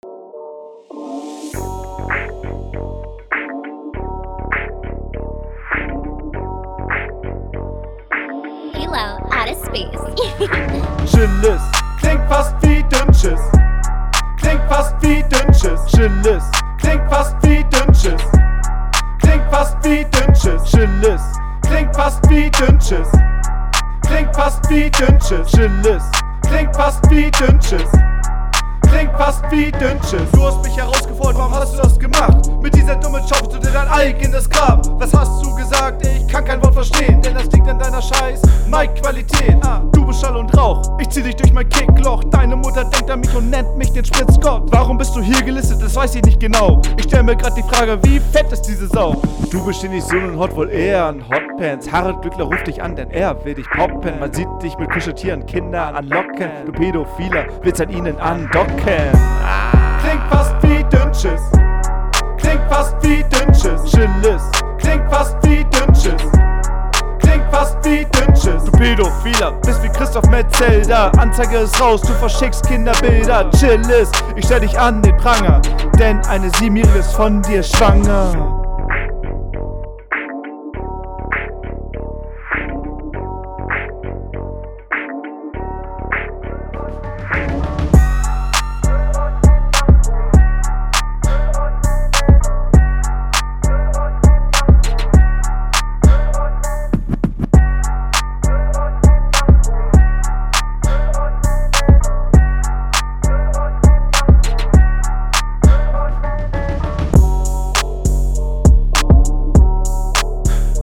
Die Stumpfe Hook ist super Du nuschelt beim schnellern Flown
es klingt furchtbar wenn du teile einer line schnell rappst aber dich verhaspelt. recorde es …